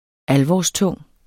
Udtale [ ˈalvɒs- ]